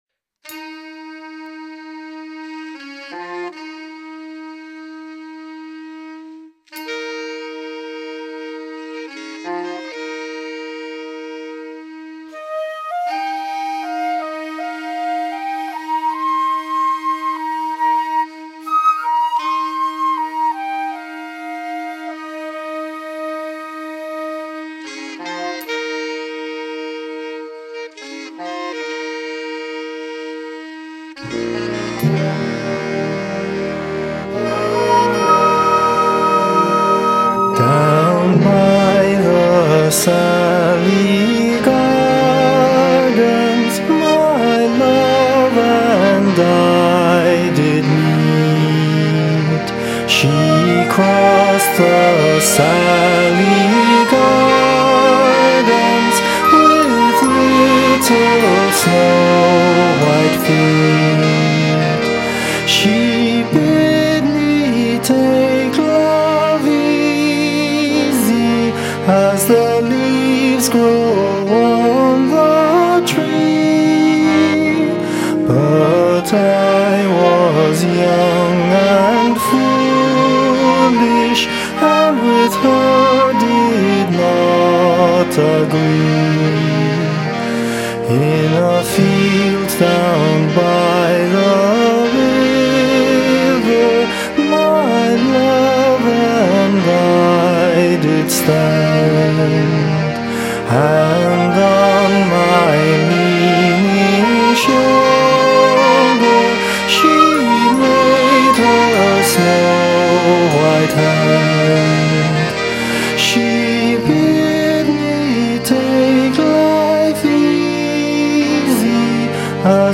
Charity Fundraiser for St Joseph's Special Needs School in Tallaght